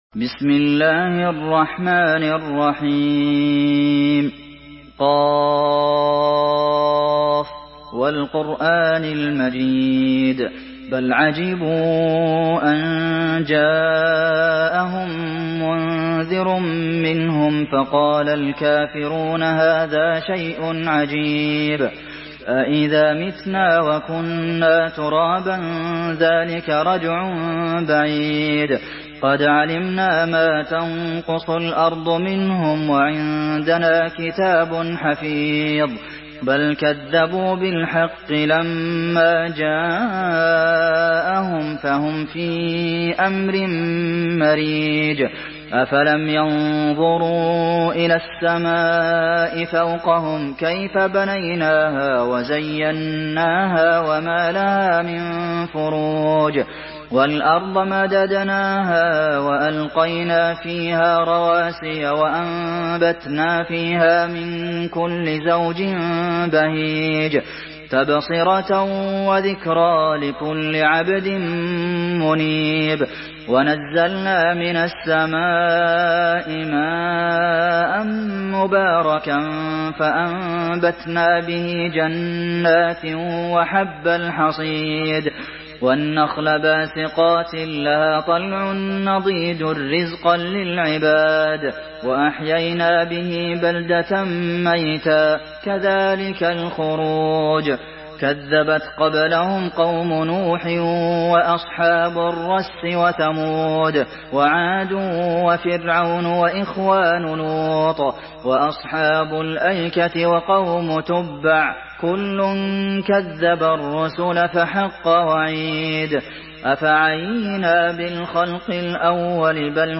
Surah ق MP3 by عبد المحسن القاسم in حفص عن عاصم narration.
مرتل